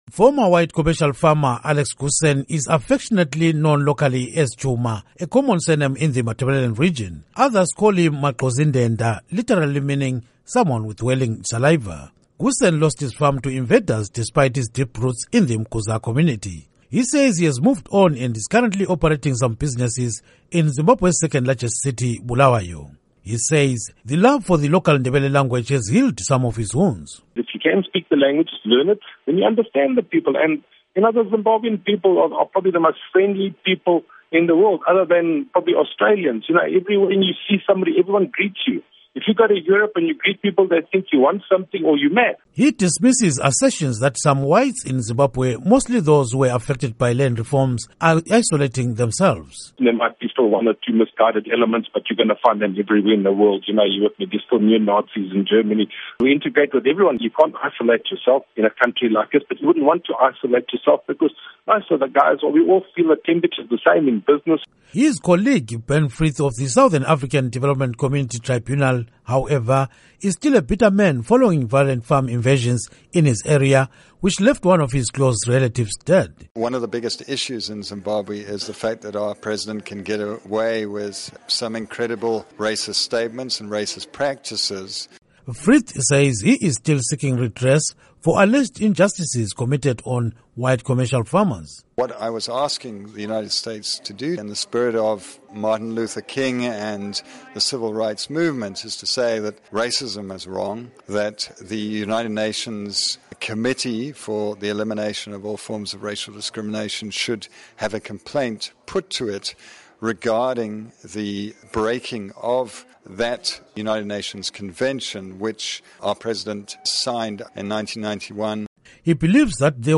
Report on White Zimbabweans